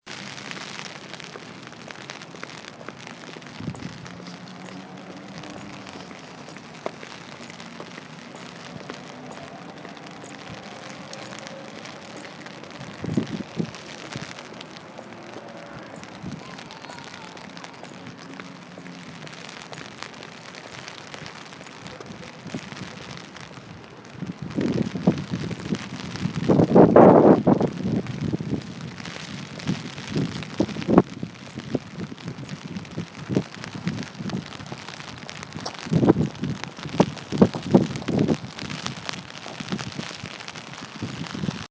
Field Recording #4
The sounds heard in this clip consists of rain patter, rain hitting my umbrella, wind, my rain jacket brushing against itself as I walk, and a very faint beeping in the distance at the end.
Rain.mp3